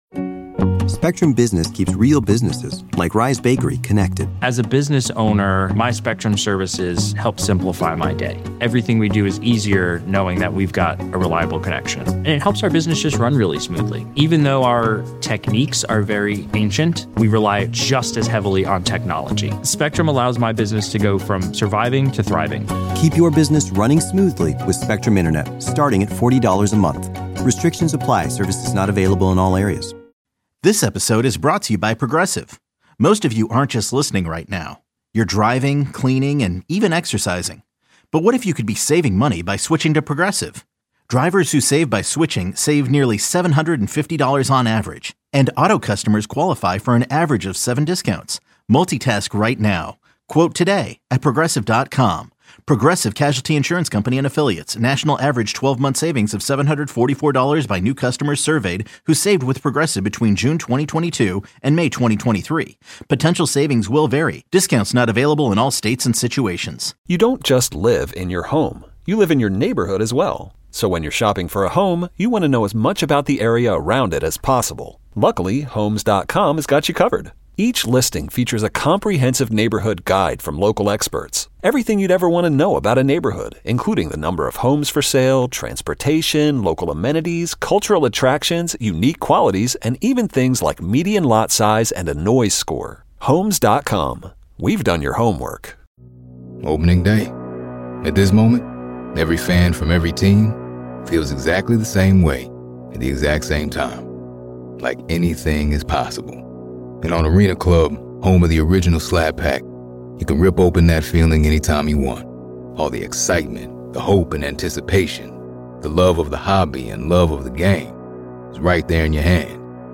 Best Interviews on 106.7 The Fan/Team 980: June 9-13, 2025